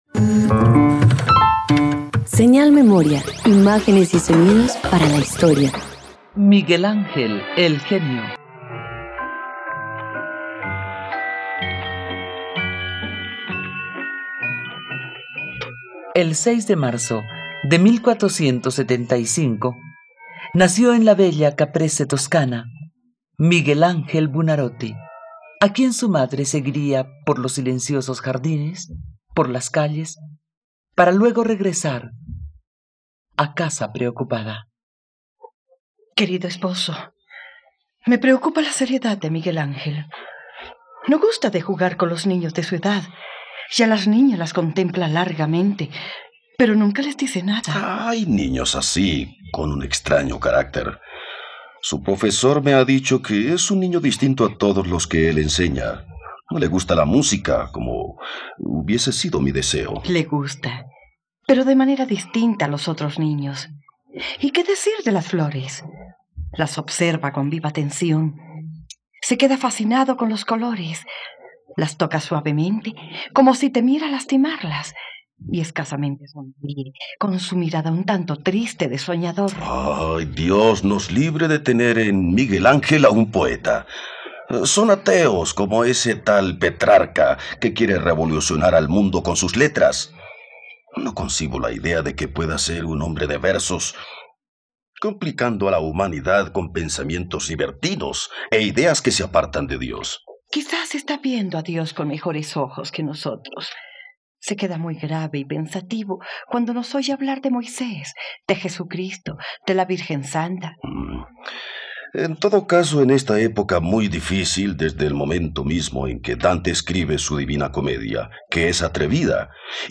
..Radioteatro. Conoce la vida del artista italiano Michelangelo Buonarroti.